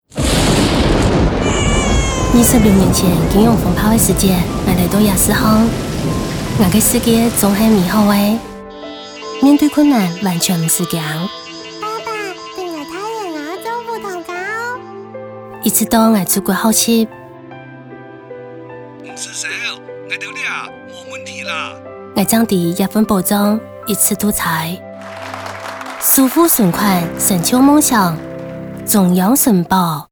台語配音 國語配音 女性配音員 客語配音
100.中央存保-素人-客語